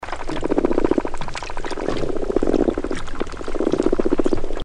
Weakfish
The weakfish makes a drumming or purring sound.
All drums are able to make a loud drumming or croaking sound by vibrating their swim bladder using special muscles.
weakfish-call.mp3